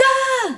Vcl Hit-C.wav